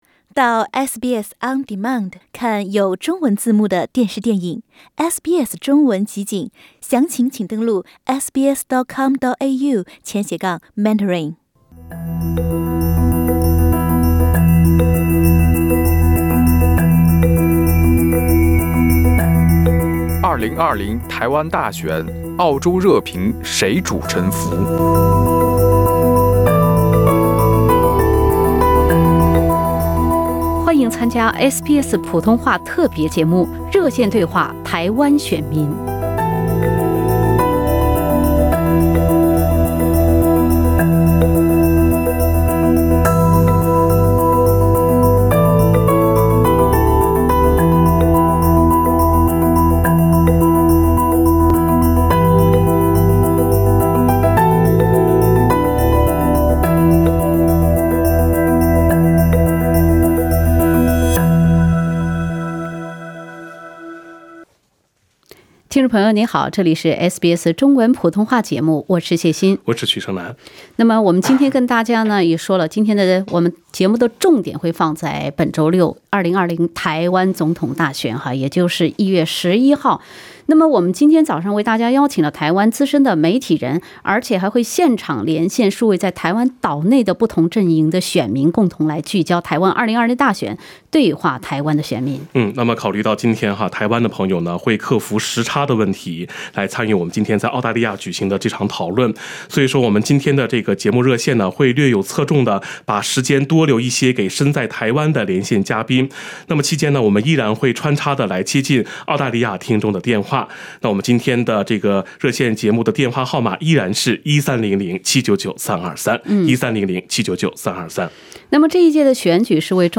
【台湾选举】热线节目-对话台湾选民：两百万人民币对赌
热线节目-SBS普通话直接连线台湾岛内不同阵营选民，倾听选民心声。